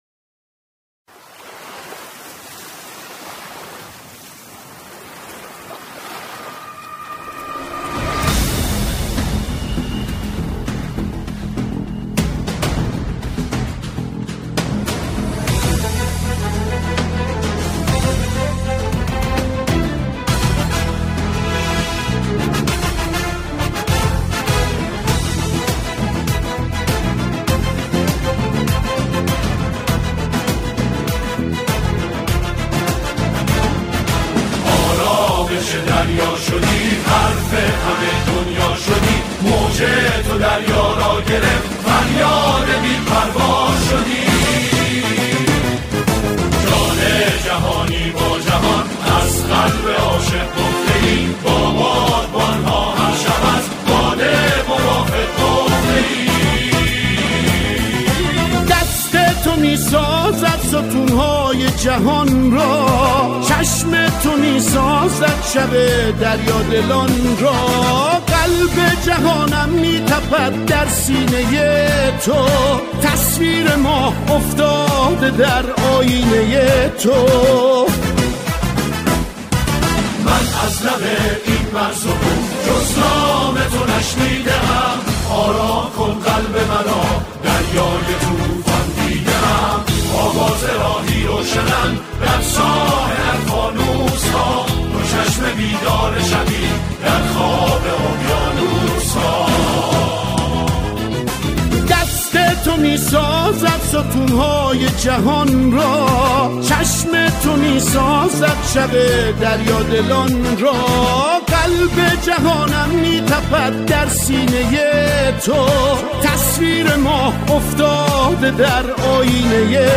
همخوانی شعری درباره “خلیج فارس”